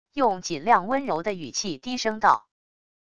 用尽量温柔的语气低声道wav音频